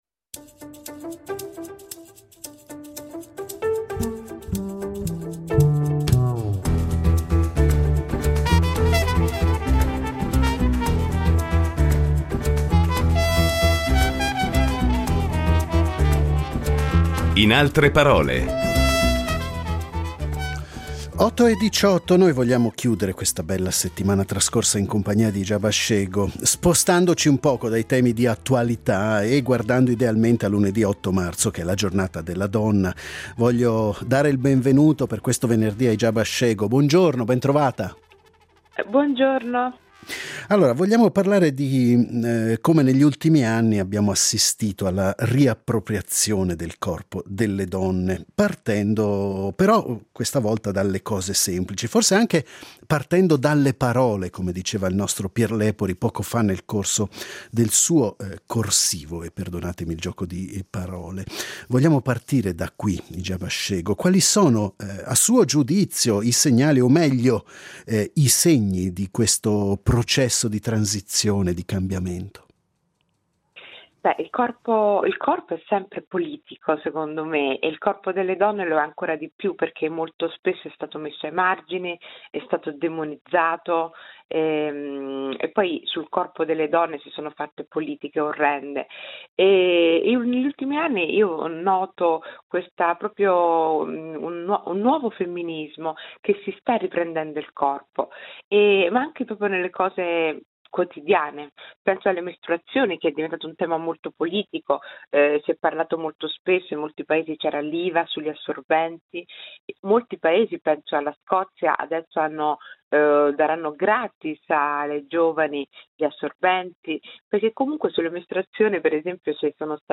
Al microfono di “In altre parole” commenterà i fatti di attualità con uno sguardo particolare rivolto proprio al tema della discriminazione, dell’integrazione e del confronto tra le culture.